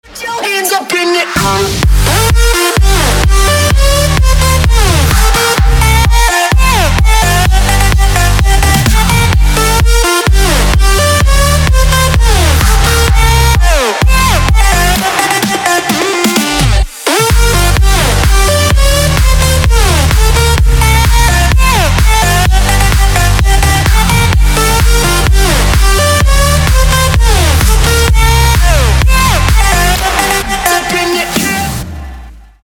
• Качество: 256, Stereo
Electronic
club
electro house
Стиль: Electro House